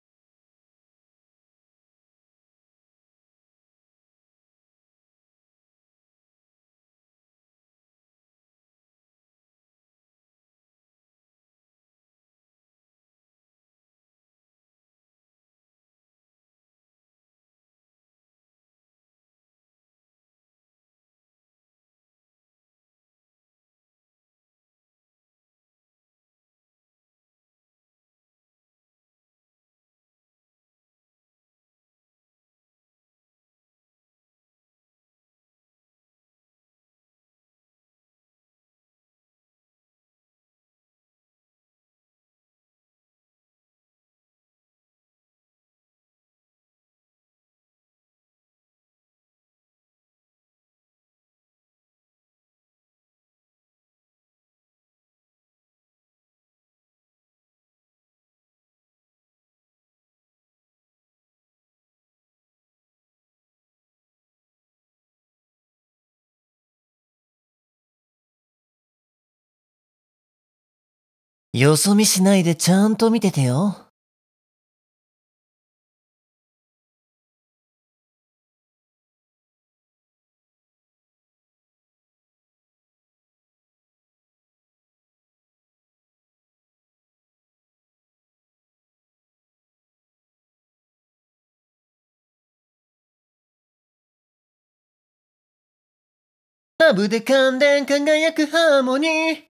台詞